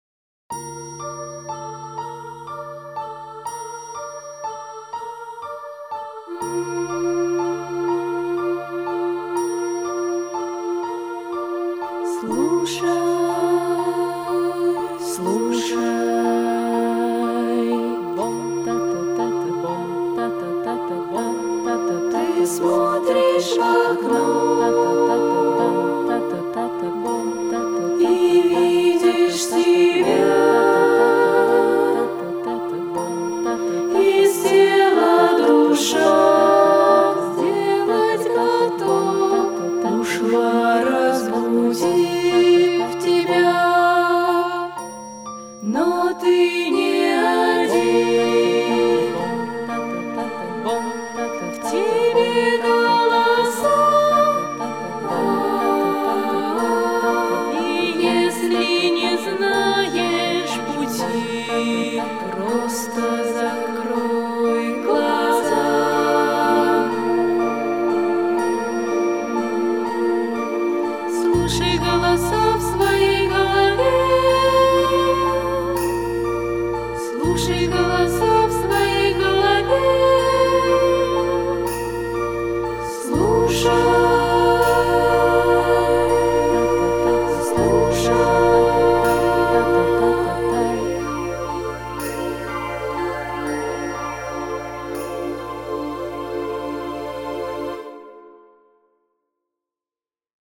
Записано в студии